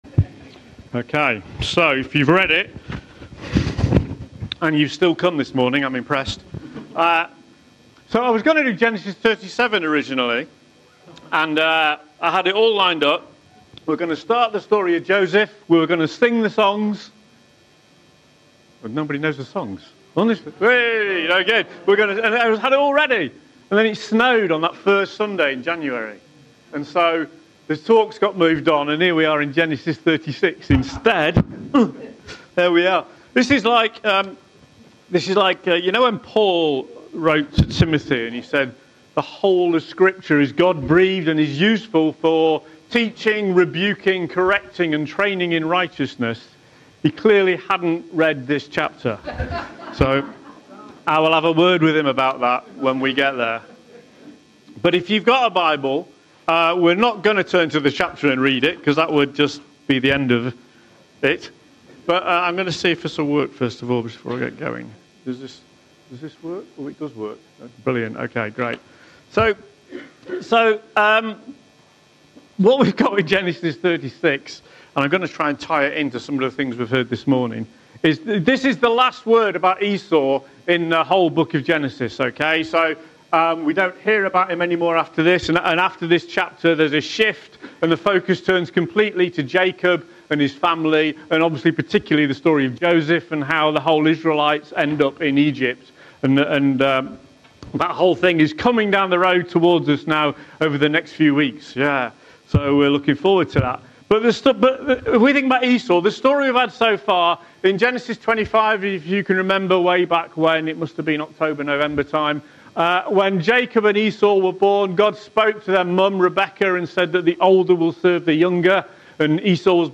Sunday Gathering – Genesis – The Bitter Root